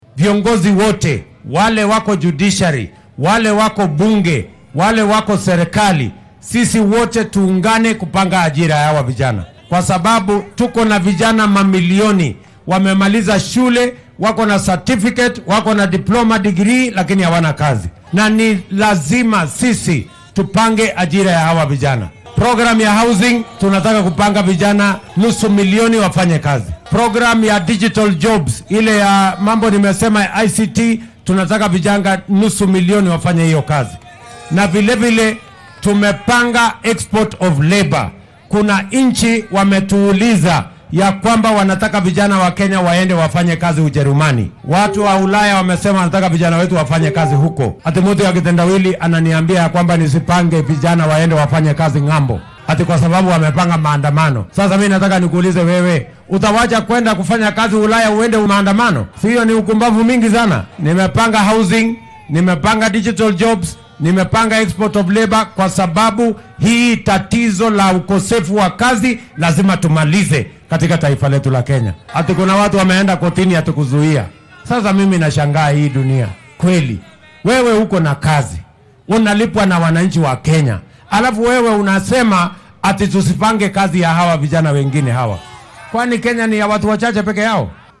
Xilli uu xariga ka jarayay waddada xiriirisa degmooyinka Kieni- Kiandege – Mworoga iyo Maraa ayuu tilmaamay in dhallinyarada dalka ay sidoo kale ka faa’iidi doonaan barnaamij iskaashi dowladeed ku saleysan oo ay uga howlgalayaan waddamo ay ka mid yihiin Jarmalka, Canada iyo Boqortooyada Sacuudi Carabiya.